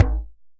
DJEM.HIT10.wav